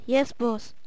horseman_ack4.wav